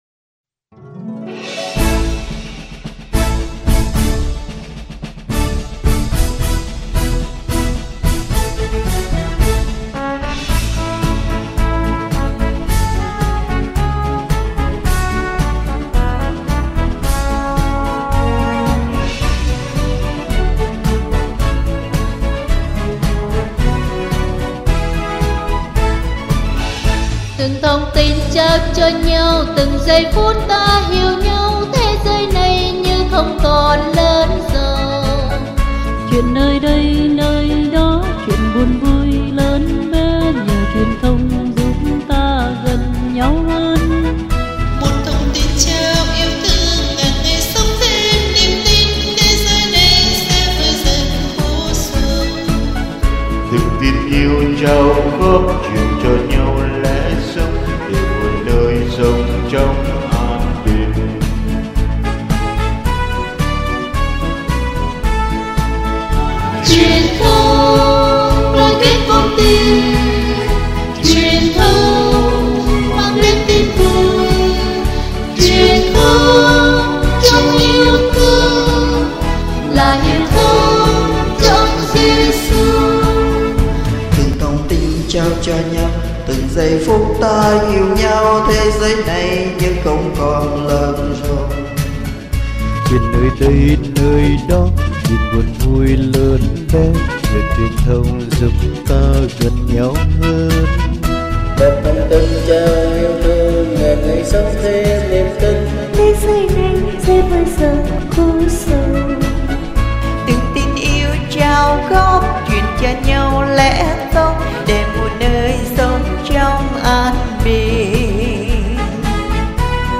Nhân dịp kỷ niệm 3 năm khai trương 14.11.15, Phòng Đạo Binh Hồn Nhỏ sẽ làm bài KaraFun tựa đề
Bài hát do ca sỹ trình bày